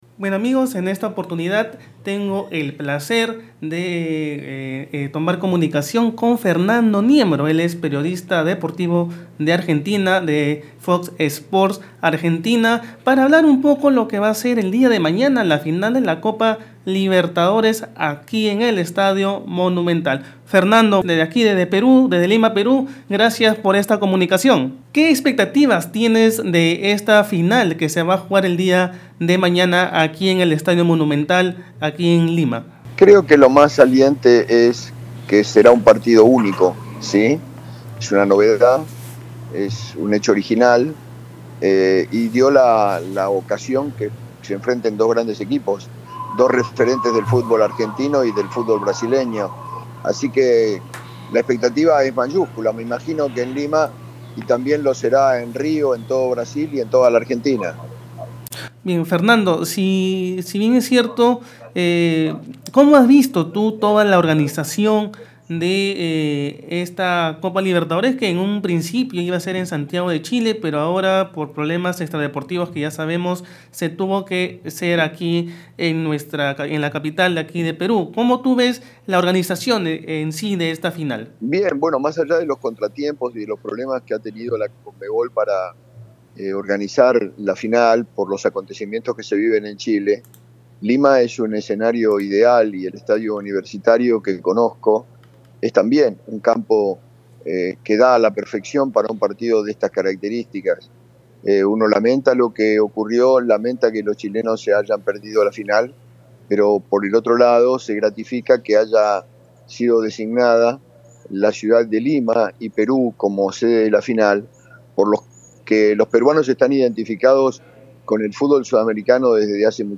Periodista argentino, Fernando Niembro, dijo que no tiene equipo favorito para el encuentro entre Flamengo y River en el Estadio Monumental.
En conversación con Red de Comunicación Regional, Niembro agregó que no tiene dudas de que Lima u otras ciudades del Perú pueden albergar grandes acontecimientos deportivos.